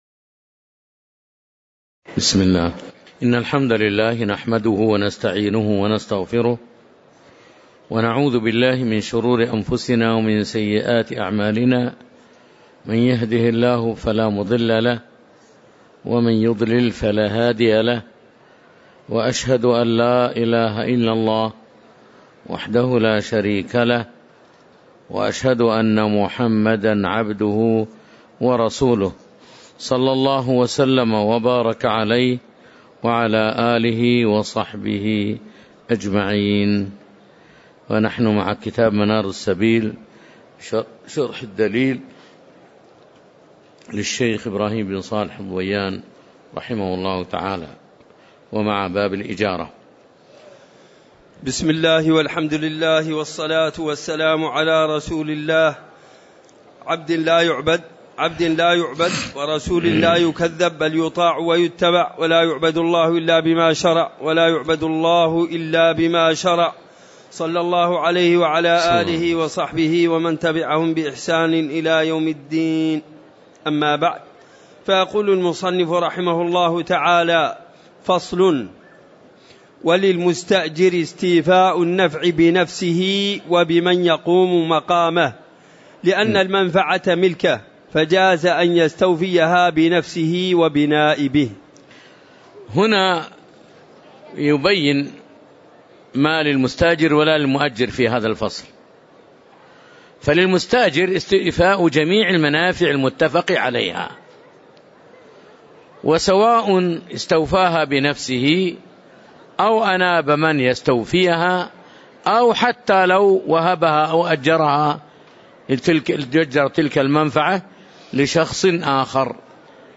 تاريخ النشر ٩ رجب ١٤٤١ هـ المكان: المسجد النبوي الشيخ